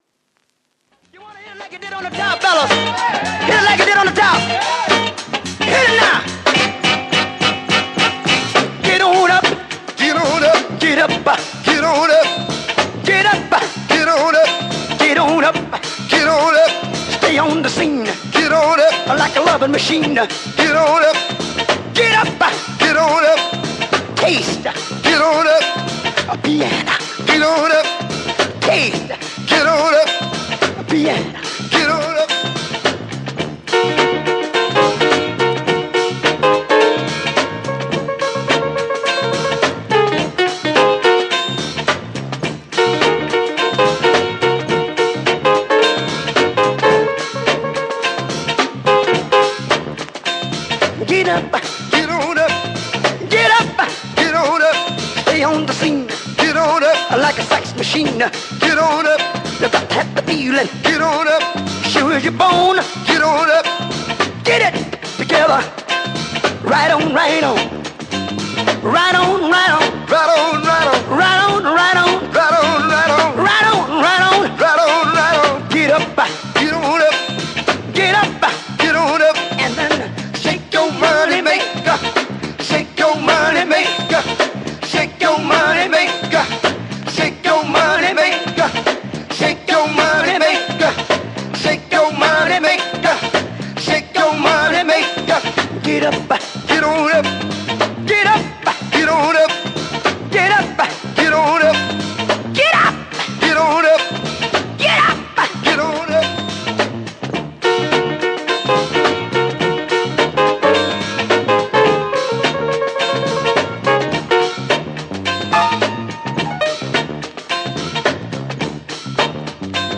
Classic Funk
Classic Funk Soul out as the French picture sleeve.